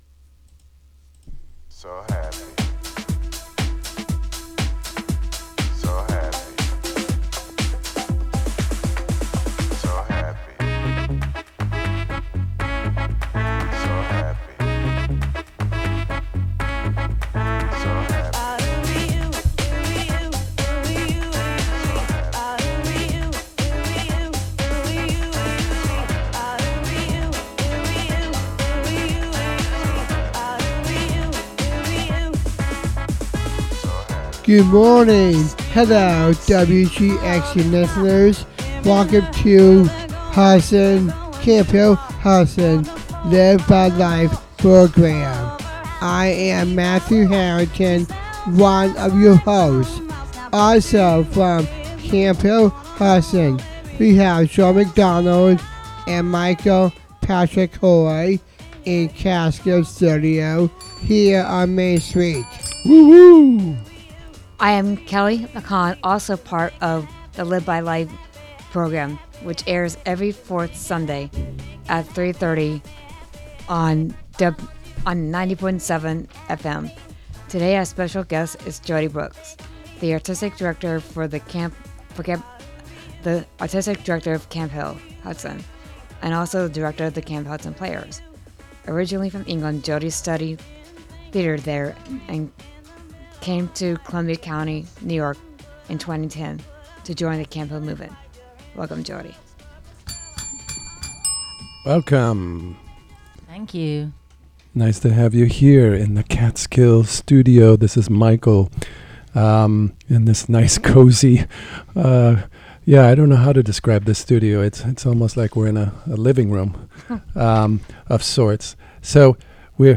Members of the Camphill Hudson Radio Group interview